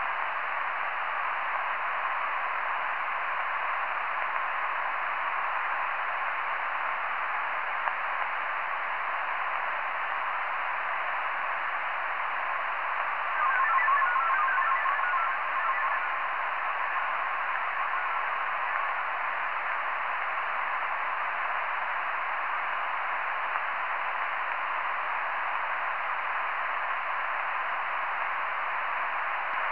one very clear burst